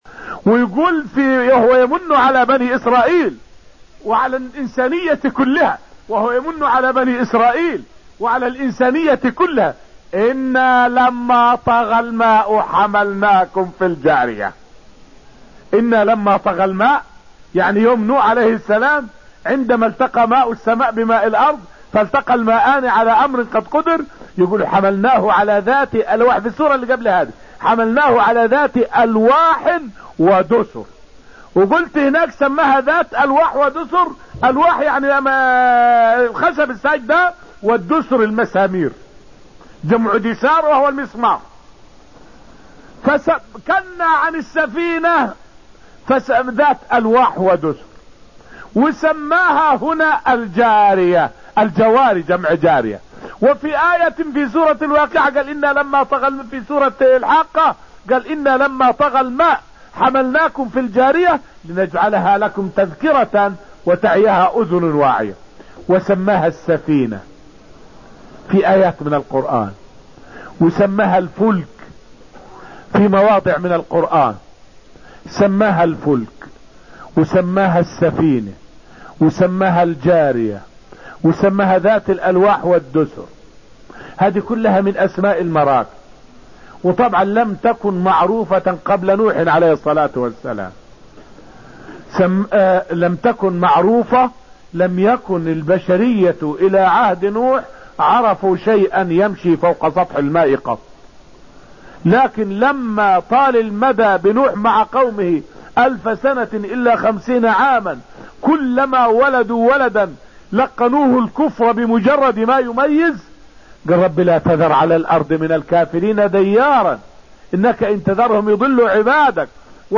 فائدة من الدرس السابع من دروس تفسير سورة الرحمن والتي ألقيت في المسجد النبوي الشريف حول منّة الله على البشرية بحمل المؤمنين في سفينة نوح.